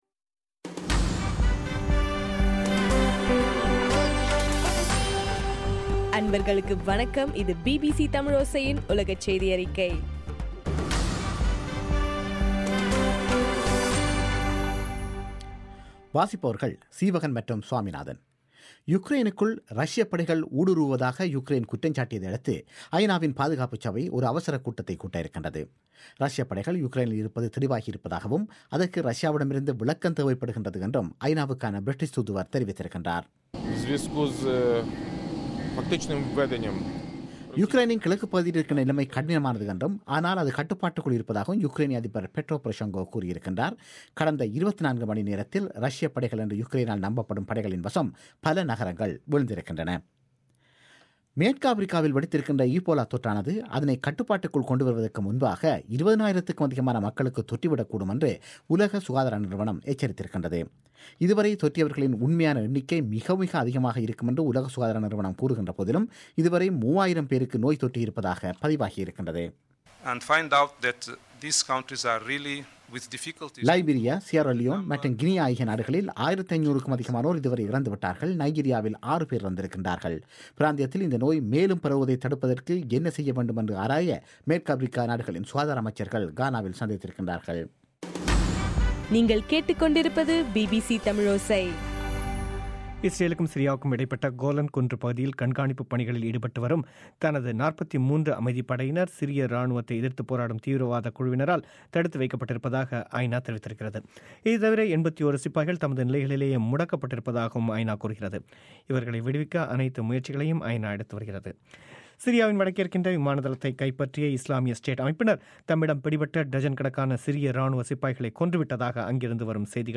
ஆகஸ்ட் 28 பிபிசியின் உலகச் செய்திகள்